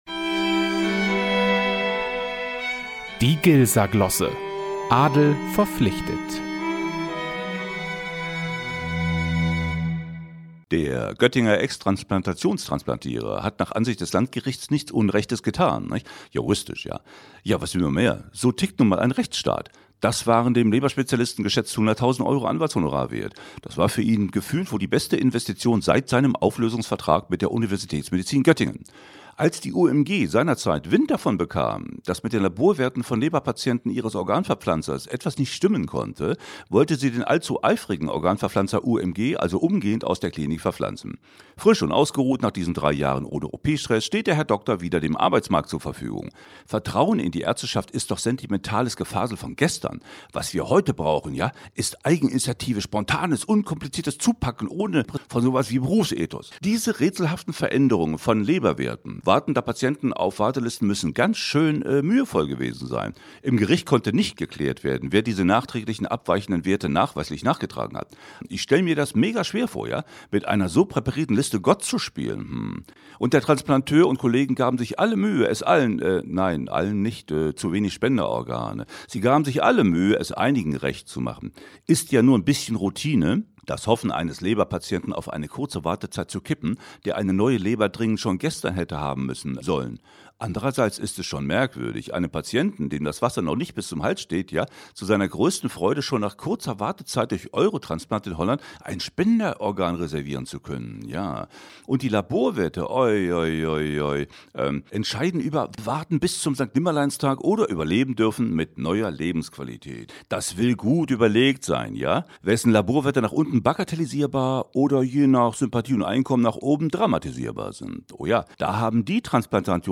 Glosse.mp3